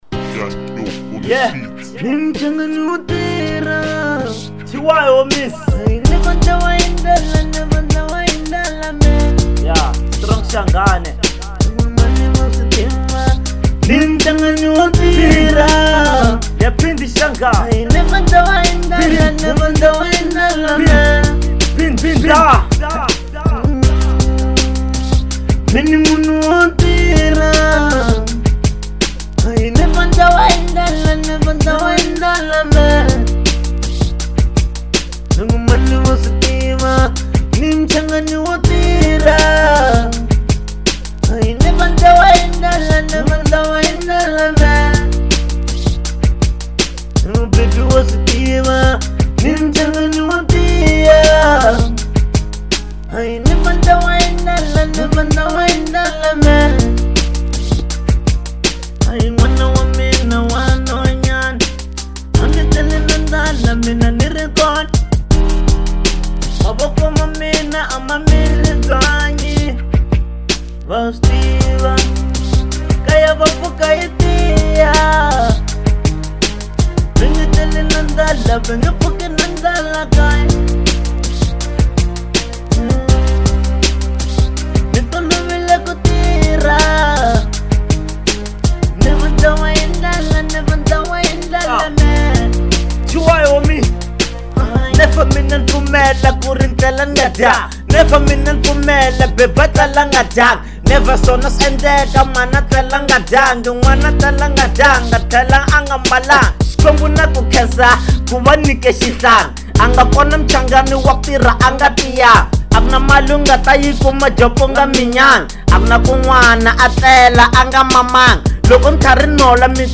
03:51 Genre : Hip Hop Size